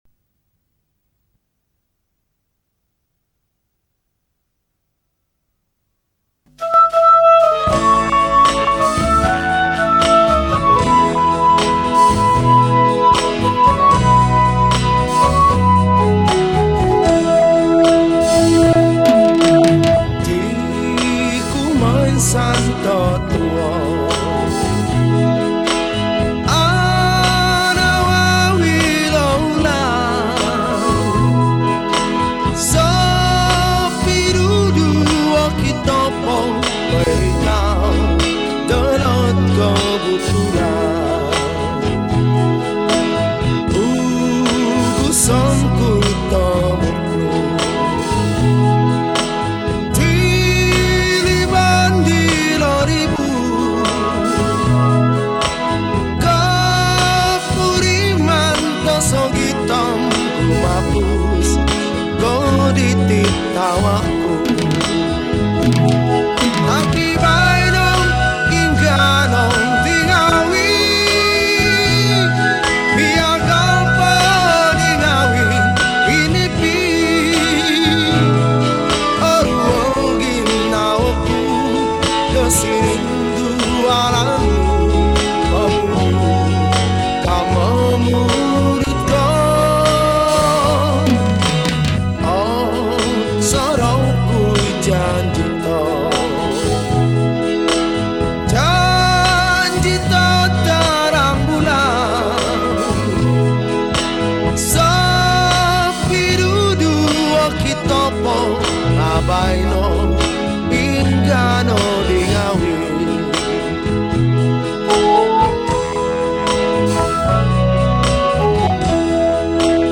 Category: Kadazan Songs
Skor Angklung